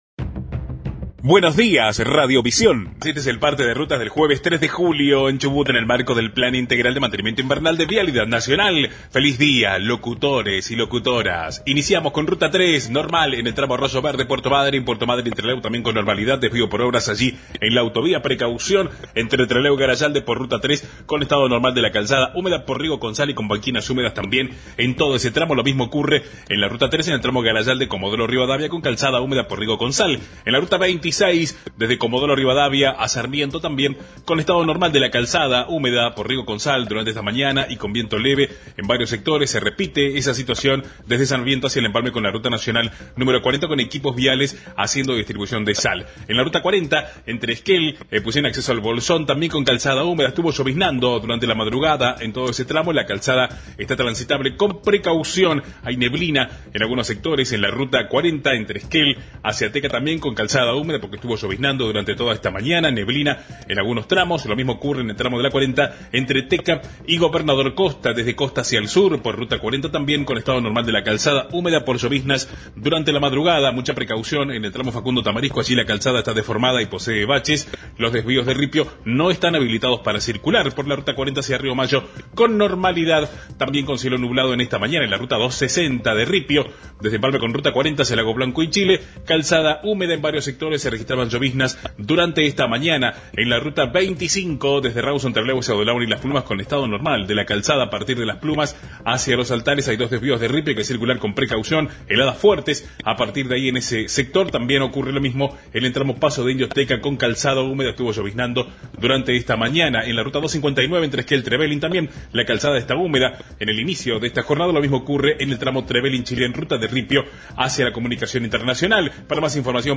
El reporte diario del estado de las rutas y caminos de Chubut